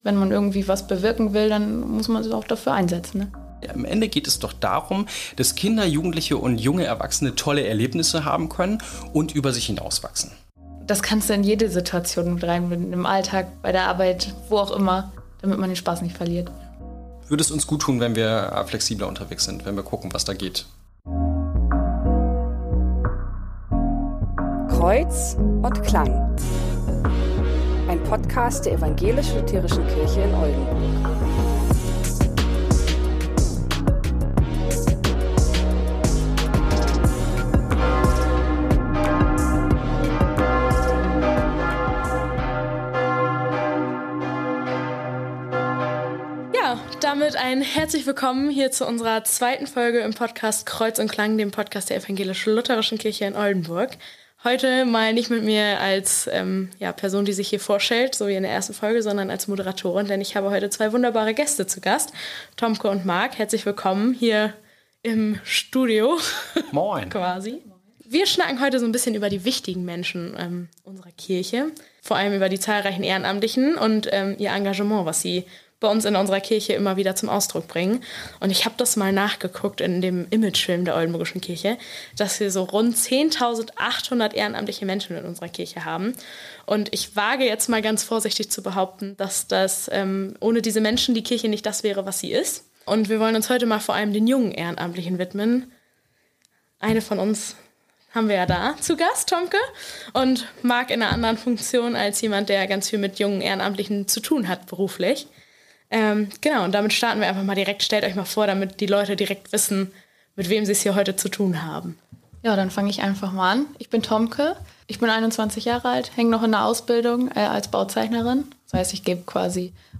Kreuz & Klang ist ein Podcast der Ev.-Luth. Kirche in Oldenburg.